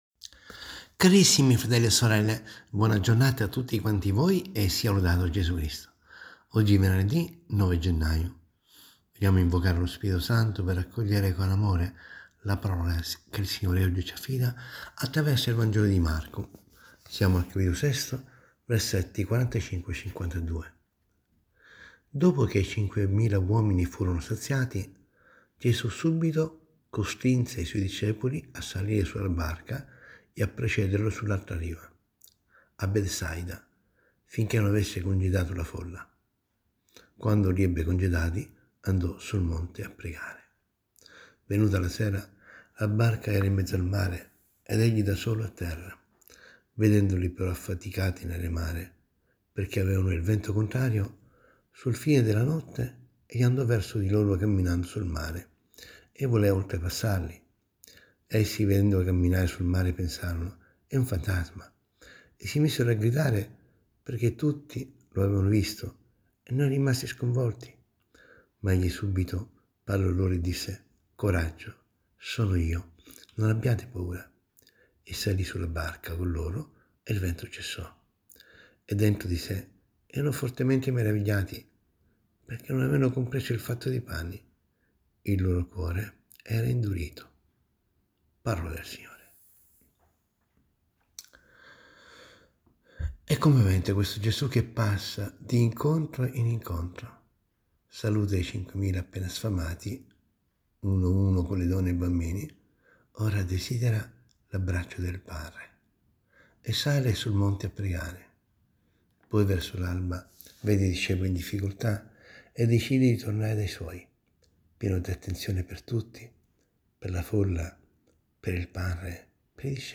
ASCOLTA  RIFLESSIONE SULLA PAROLA DI DIO, - SE L'AUDIO NON PARTE CLICCA QUI